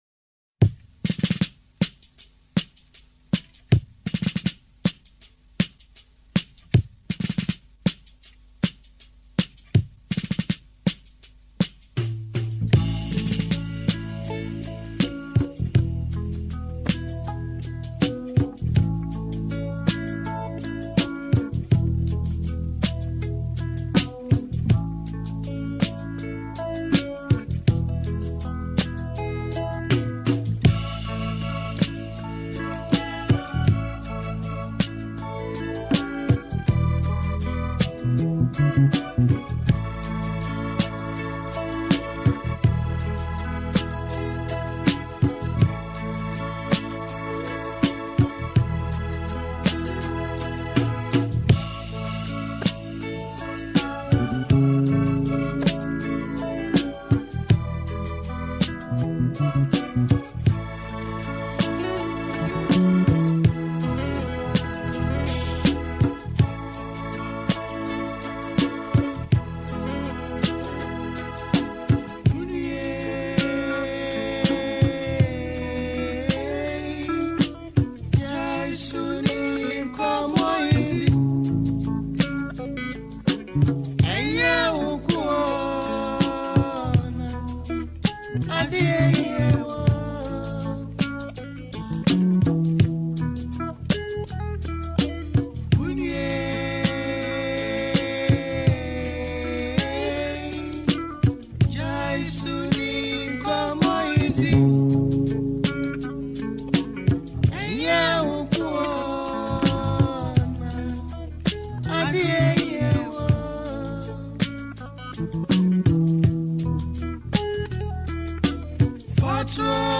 Genre | Highlife